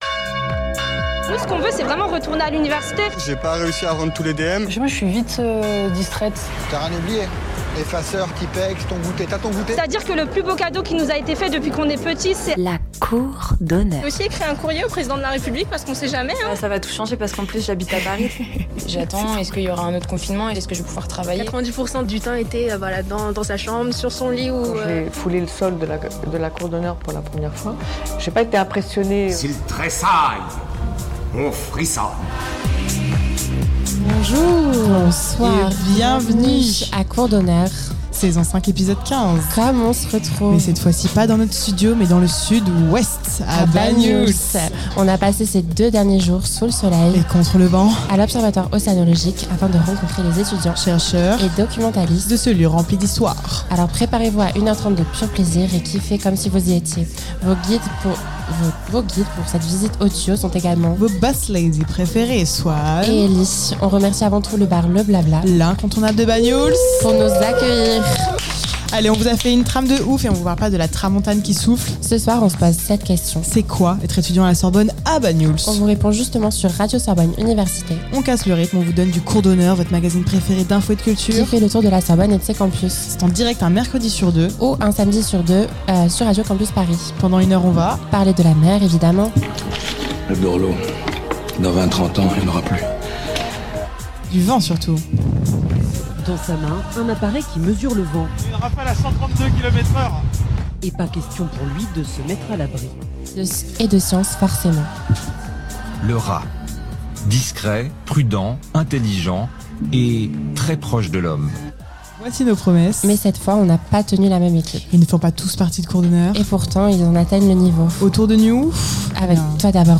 Emission spéciale à l'Observatoire Océanologique de Banyuls-sur-mer